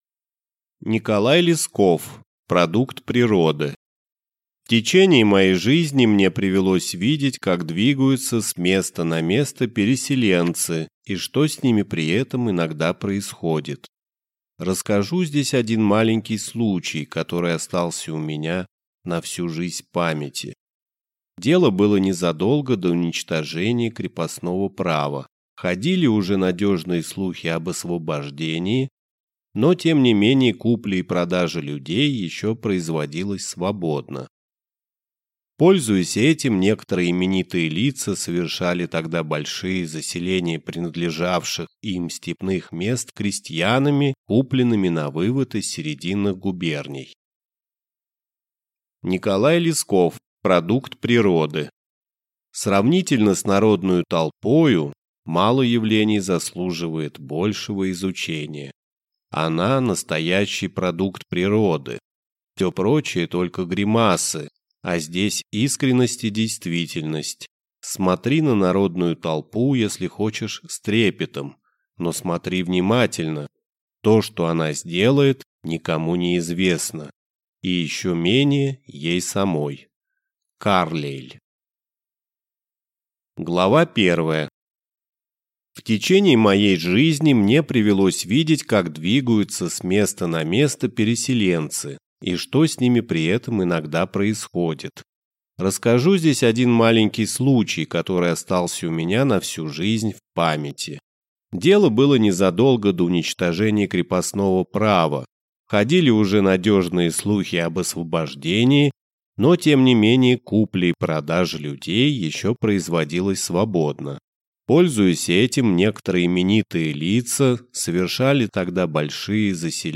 Аудиокнига Продукт природы | Библиотека аудиокниг